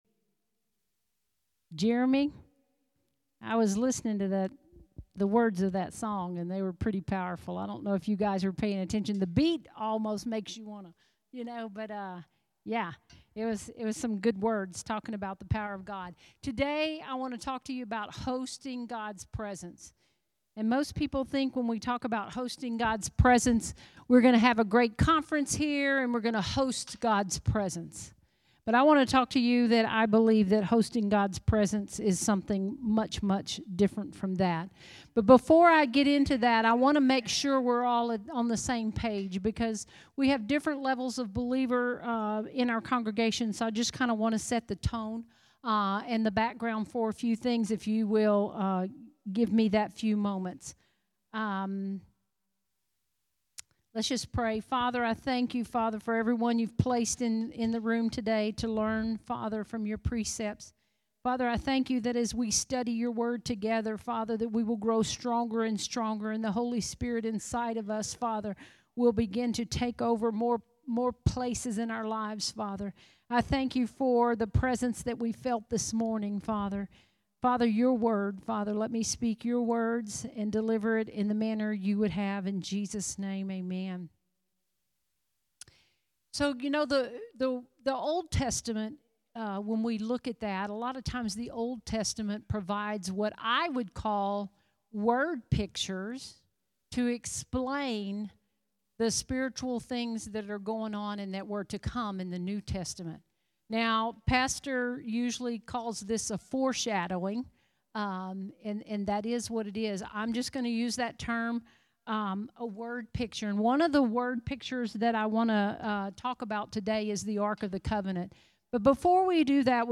Sermons | Harvest Time Church